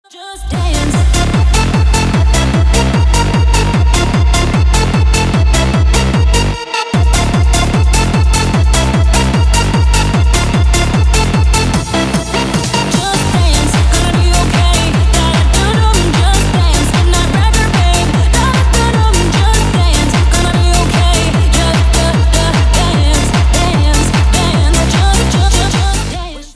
Клубные отрывки на рингтон
Techno ремикс СКАЧАТЬ РИНГТОН